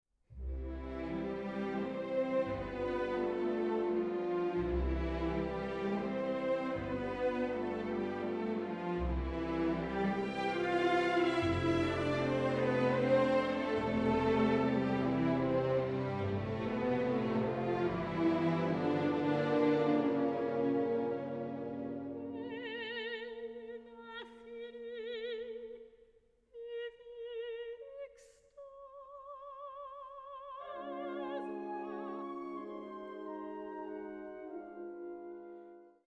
Sacred and Profane Arias